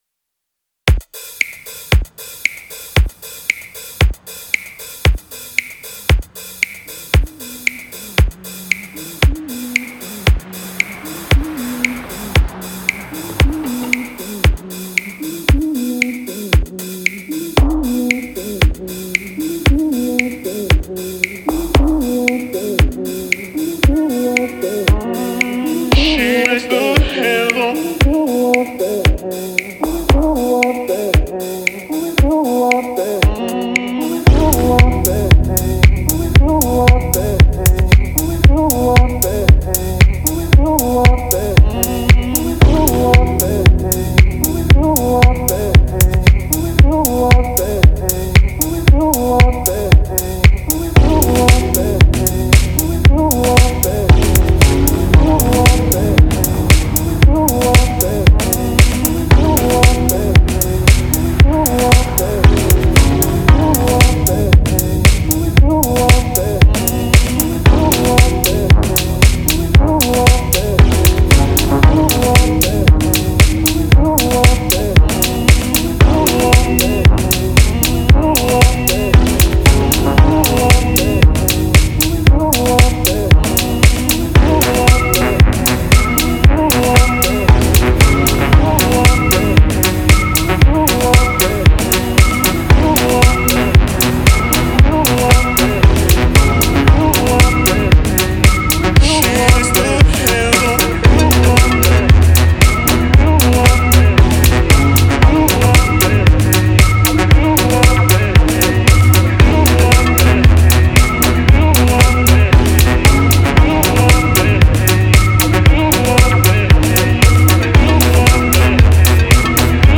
new producer who likes house and electronic beats.